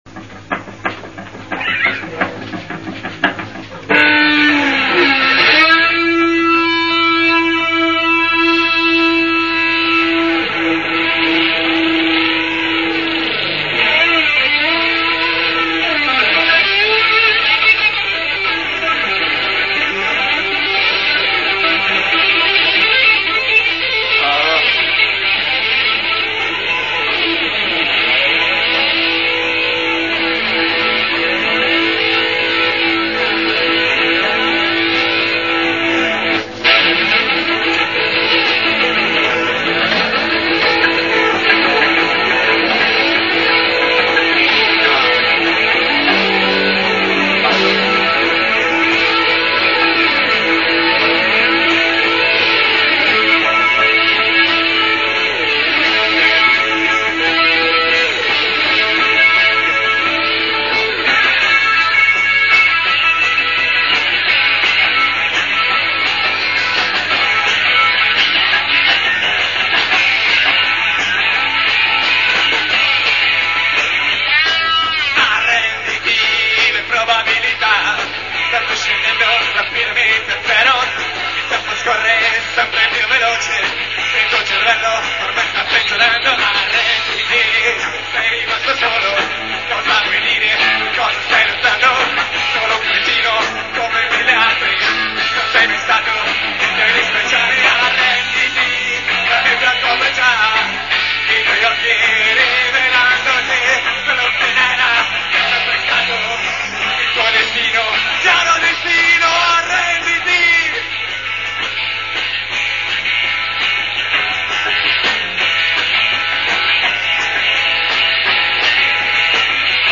voce
chitarra
basso
batteria